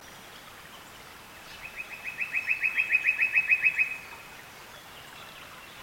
Treecreepers have quite a range of calls/songs.
It can also give a lovely mellow trill, as recorded here